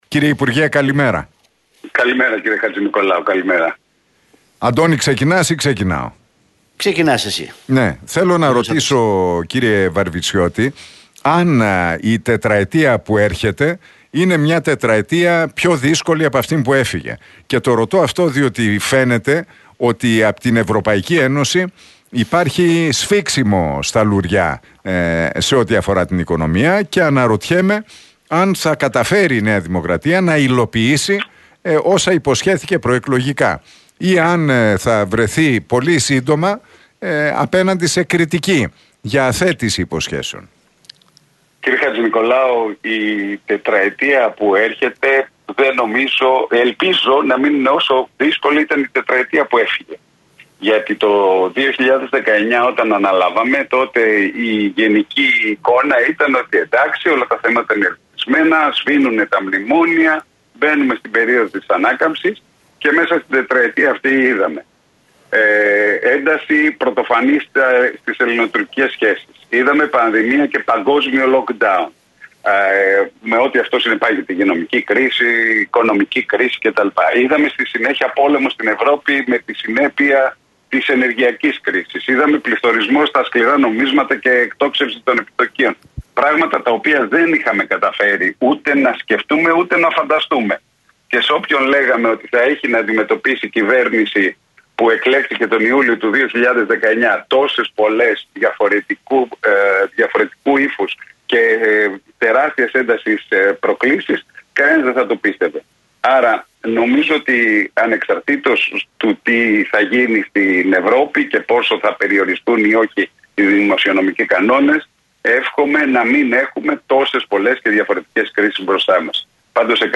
Ο Μιλτιάδης Βαρβιτσιώτης μίλησε στην εκπομπή του Νίκου Χατζηνικολάου